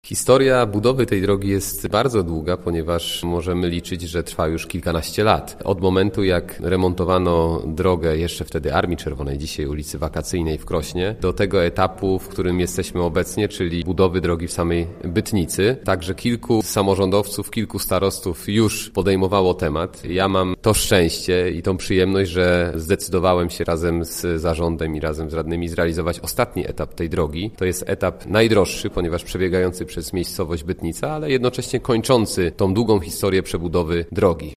– No i dobrnęliśmy do finału, czyli wyremontujemy ostatni odcinek – mówi Grzegorz Garczyński, starosta krośnieński.